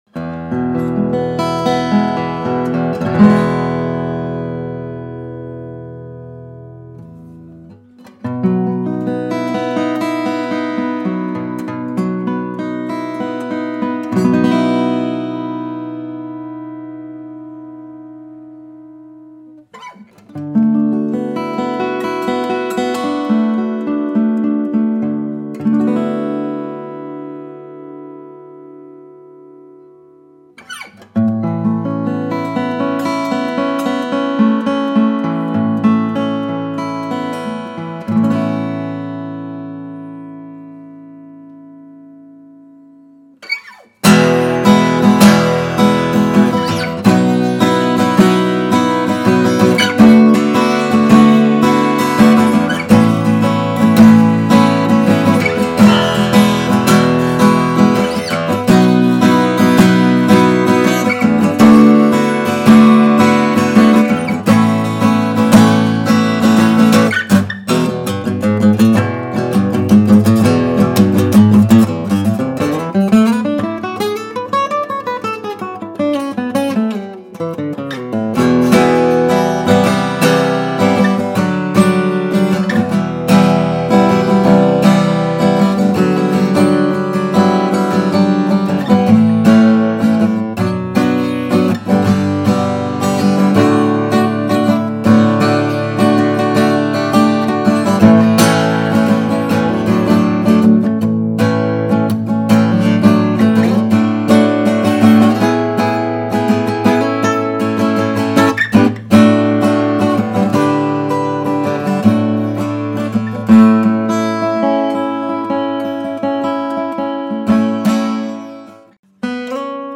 This will give you a vintage tone with a Tighter low end (controlled, less boom) and more focus with excellent note definition. She has a strong midrange punch with a more dry / fundamental-heavy tone and excellent clarity when strummed hard.
This classic Martin dreadnought captures the essence of that mid-century Martin with the unmistakable tone and feel!